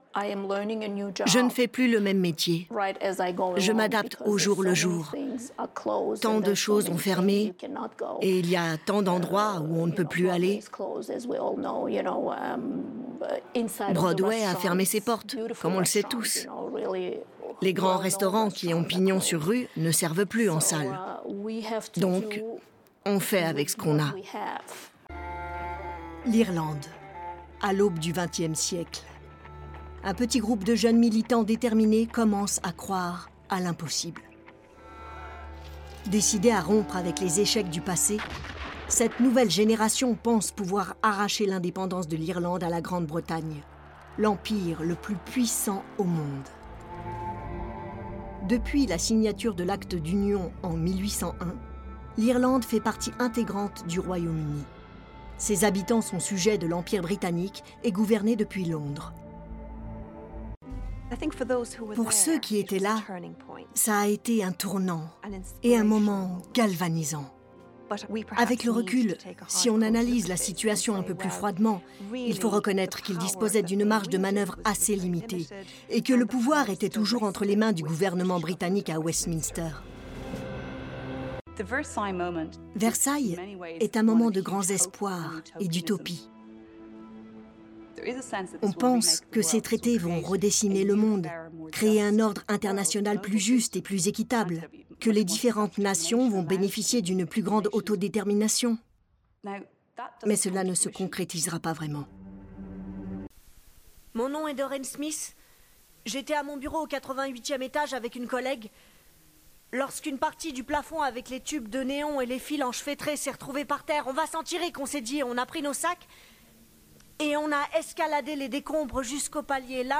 Bandes-son
Voix off
30 - 60 ans